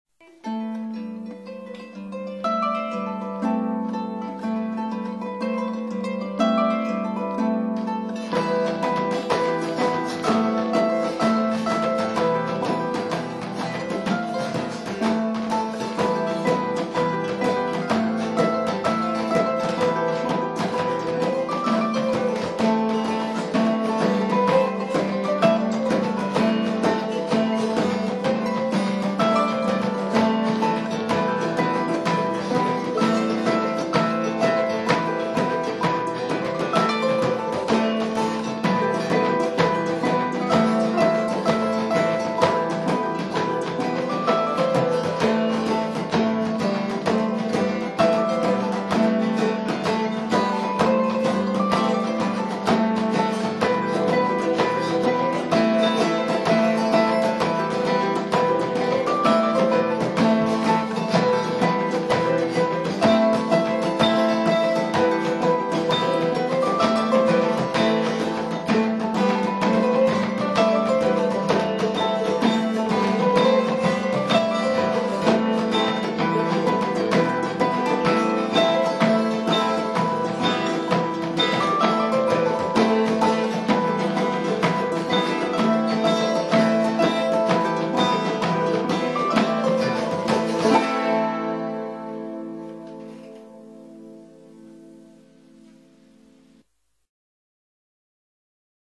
Recorded at Flying Fiddle Studio
Harp
Octave Mandolin
Guitar
Bodhran